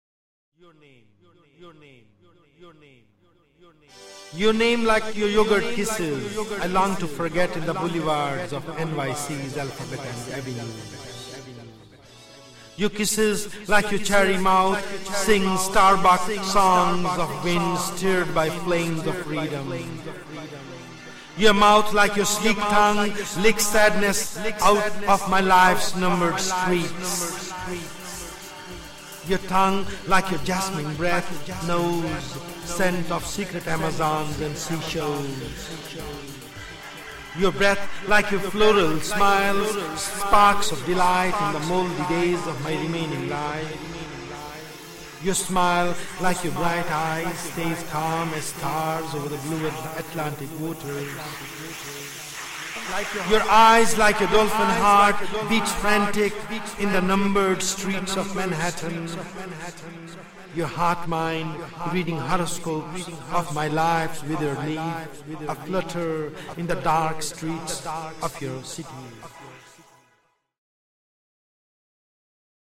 Yuyutsu Sharma reading "Your Name"